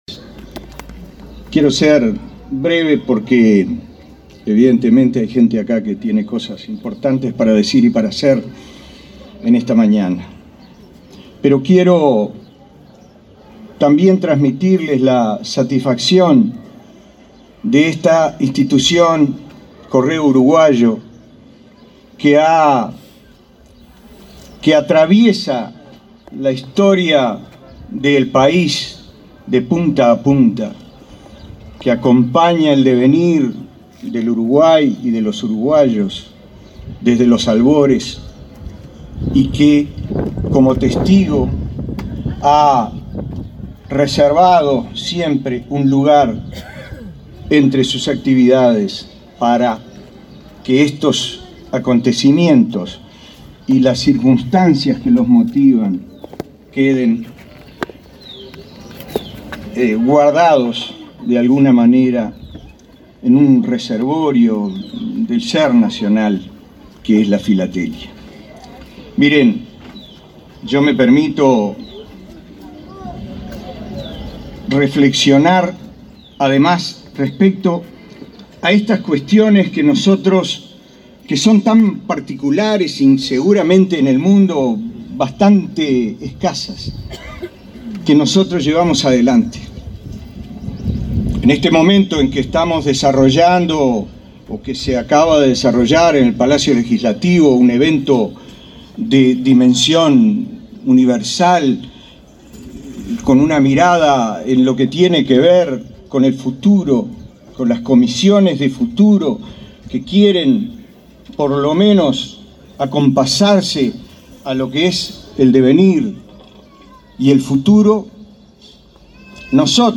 Palabra de autoridades en acto en San Ramón, Canelones
Palabra de autoridades en acto en San Ramón, Canelones 28/09/2023 Compartir Facebook X Copiar enlace WhatsApp LinkedIn El vicepresidente del Correo, Julio Silveira; el director de la Comisión de Patrimonio Cultural de la Nación, William Rey, y el ministro de Educación y Cultura, Pablo da Silveira, participaron, este jueves 28 en el Complejo Educativo Tapié-Piñeyro en San Ramón, Canelones, del lanzamiento del Día del Patrimonio 2023.